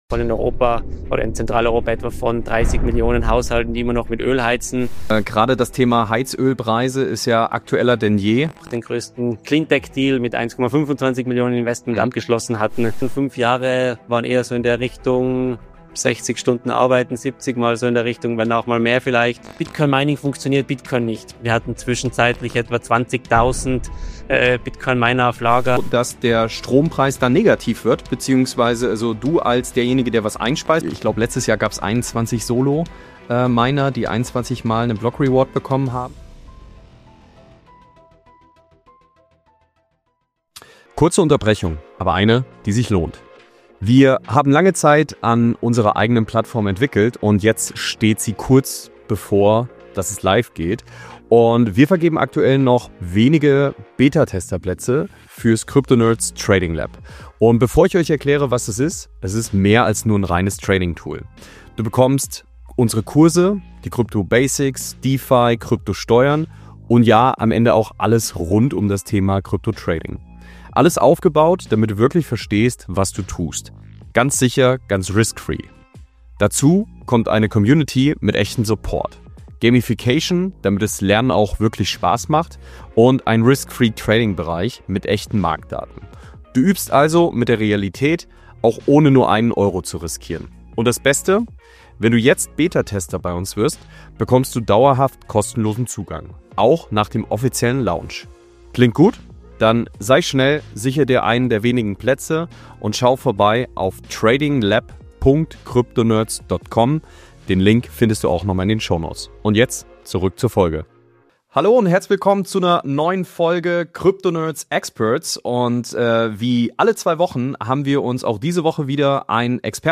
Experts | Bitcoin-Heizung: Wie Mining zum Energietool wird | Interview